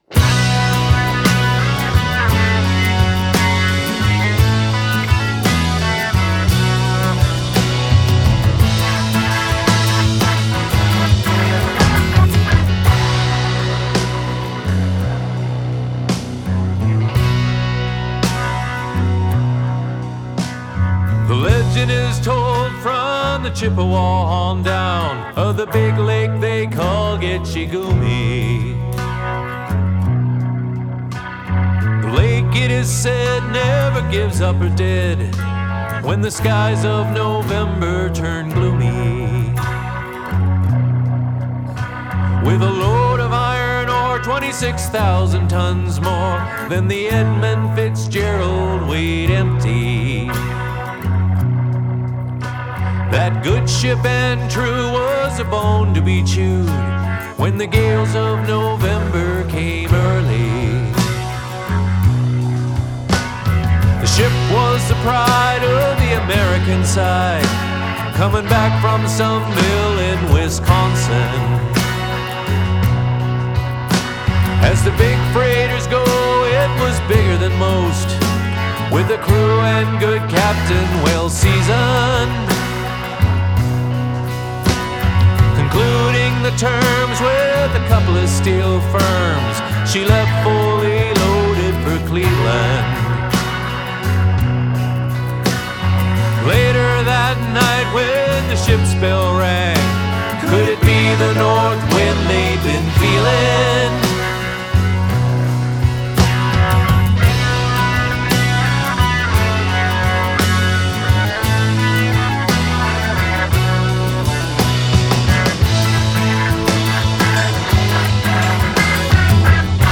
drums
bass
myself on guitar and vocals